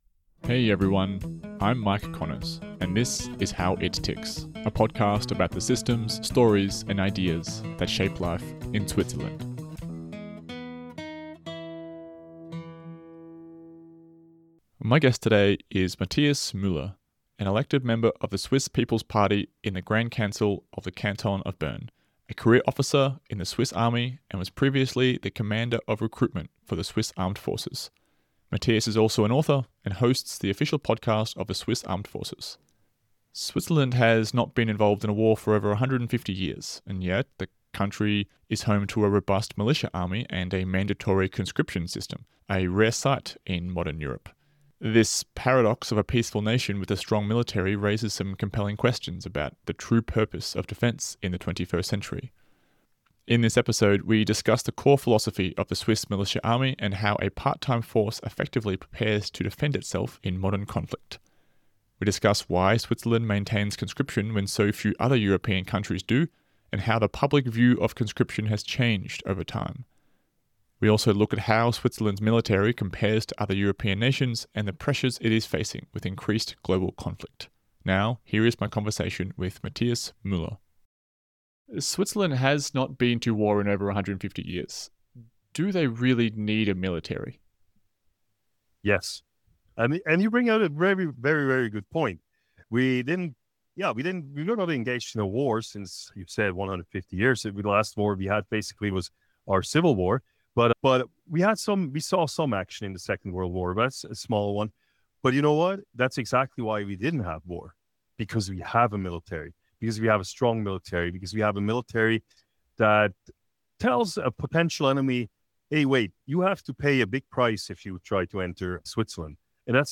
My guest today is Mathias Müller, an elected member of the SVP party in the Grand Council of the Canton of Bern.